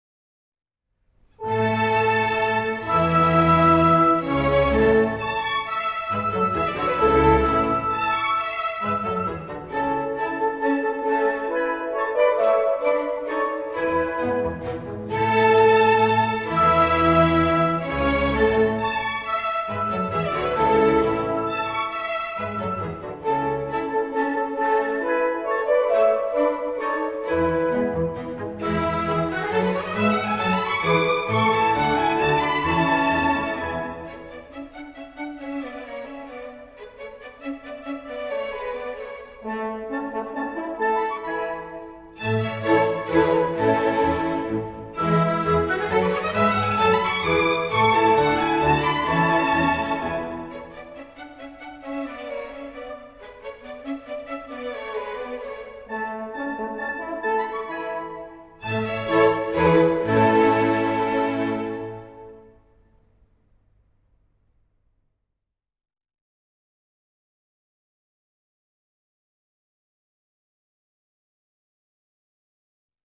Menuetto (alternativo)